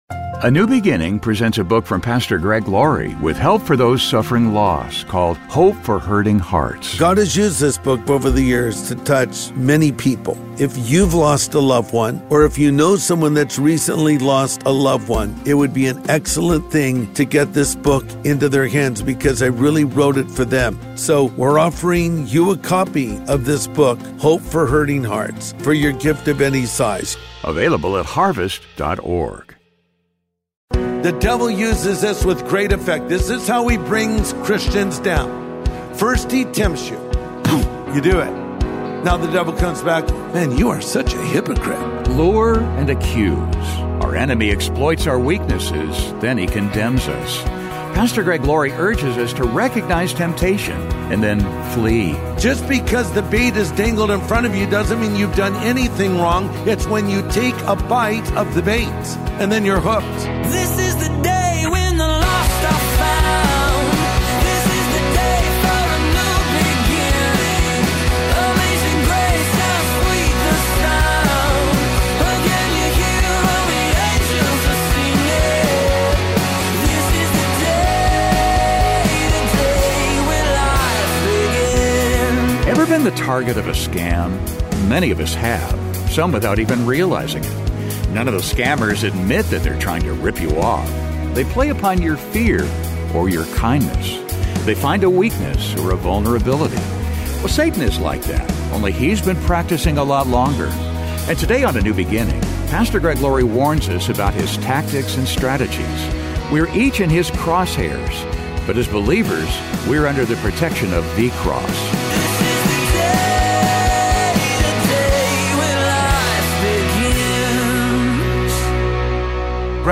Satan is like that, only he's been practicing a lot longer. And today on A NEW BEGINNING, Pastor Greg Laurie warns us about his tactics and strategies.